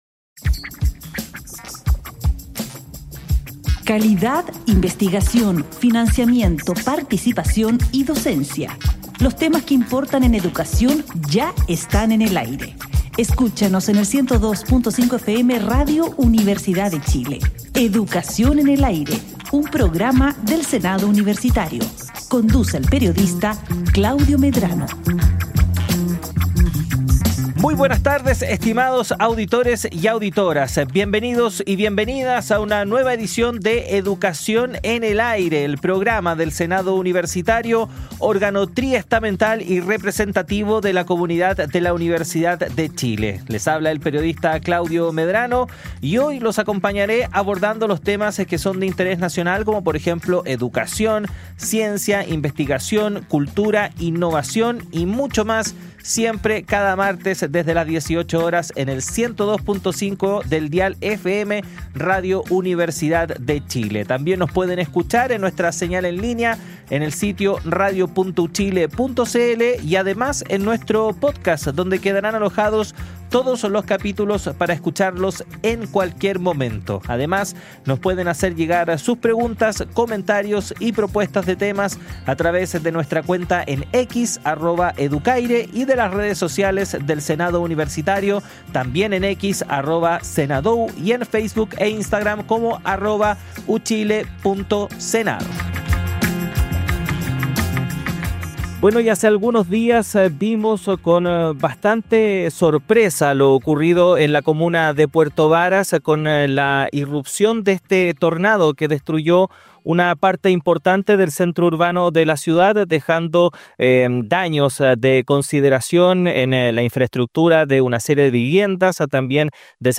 Educación en el Aire: Entrevista